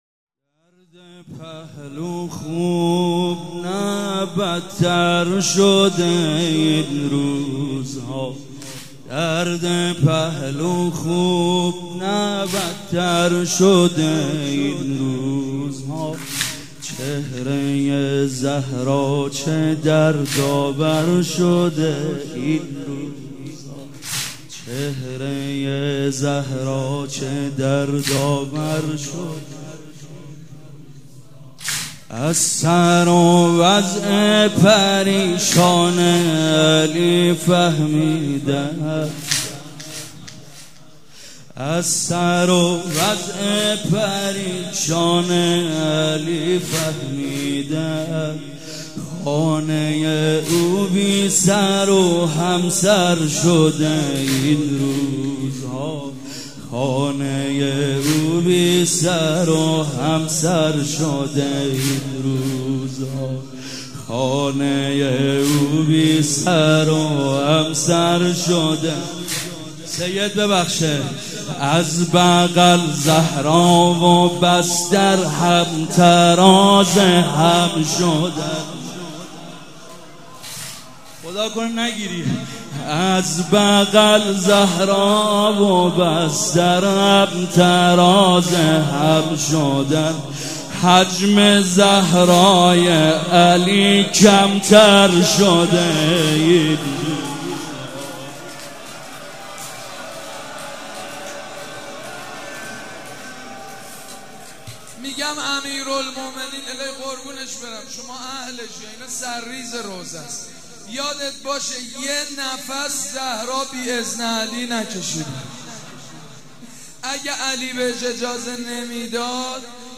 شب پنجم فاطميه دوم١٣٩٤
مداح
مراسم عزاداری شب شهادت حضرت زهرا (س)